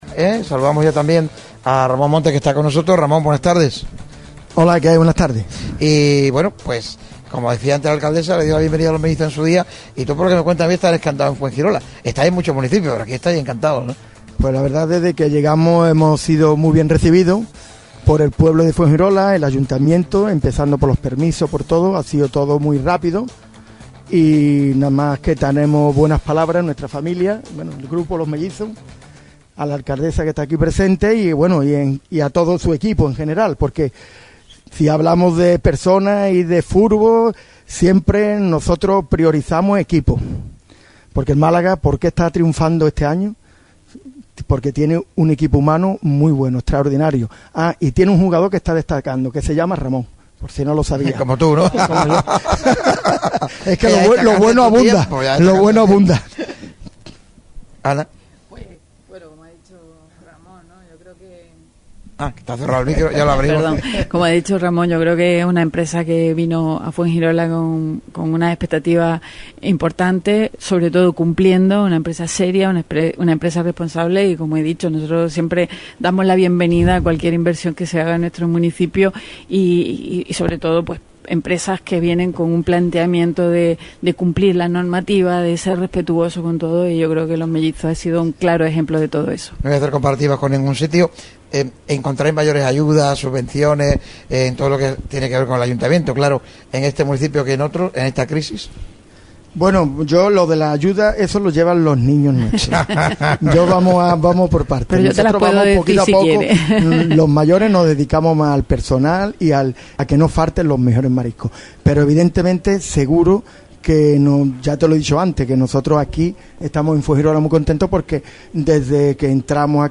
La emisora que vive el deporte trasladó su estudio móvil directo al centro de Fuengirola.